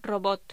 Locución: Robot
Sonidos: Voz humana